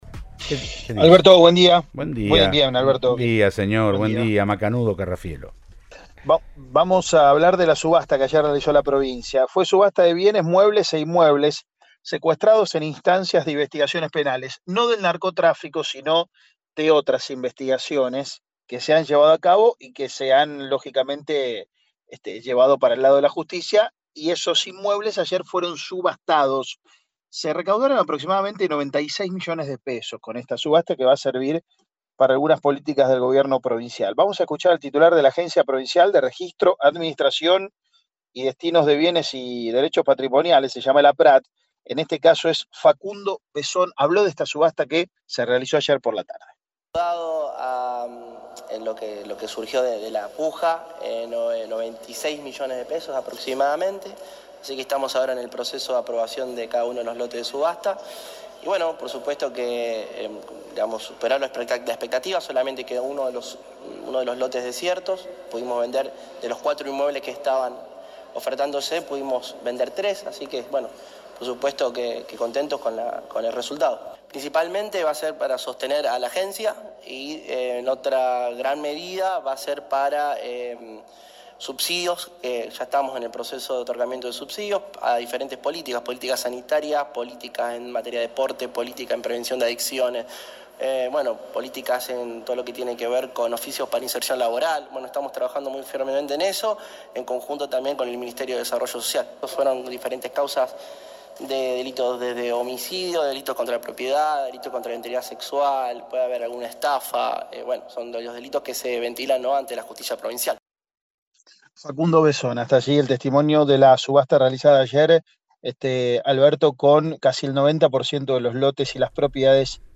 Así lo confirmó a Cadena 3 Rosario Facundo Bressón, titular de APRAD y contó para qué se utilizará el dinero.
El titular de APRAD Facundo Bressón le dijo al móvil de Cadena 3 Rosario que “estamos en el proceso de aprobación de lotes de subasta que superó las expectativas ya que solo quedo un lote desierto”.